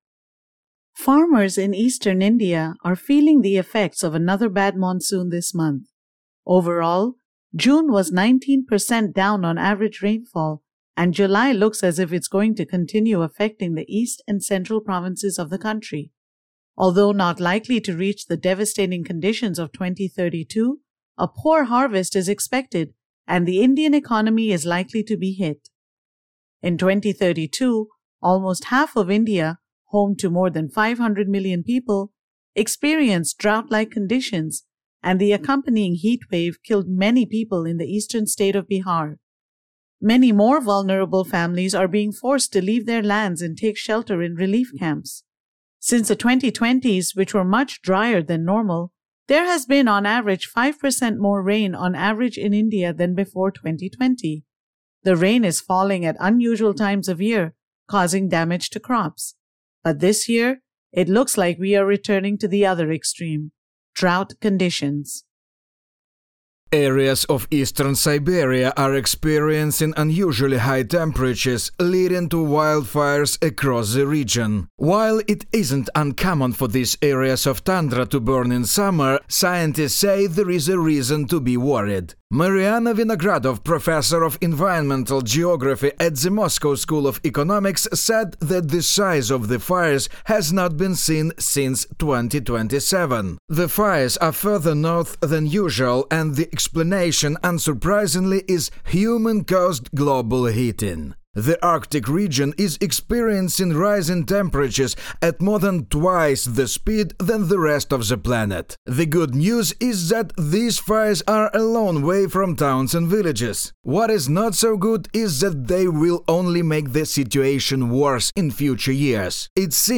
Weather_reports.mp3